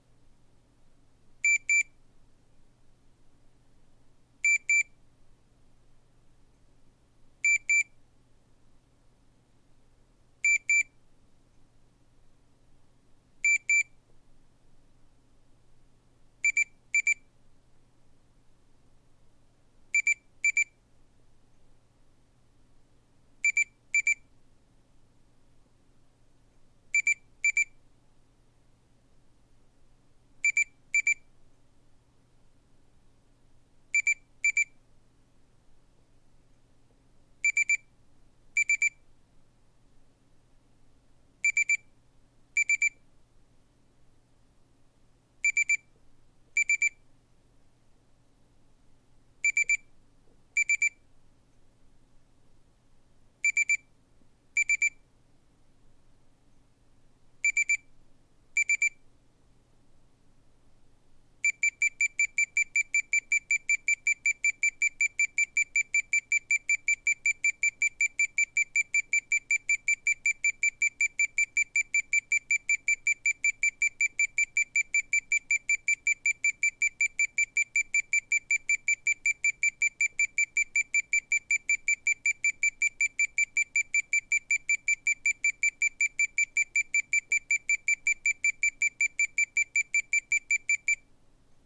Das dezente Tonsignal ist sowohl für die Meditation, wie auch zum Wecken noch passend.
Im Weckmodus wird erst sanft geweckt, später wird das Signal immer intensiver.
Klangdatei Timer Kompakt Wecker (MP3)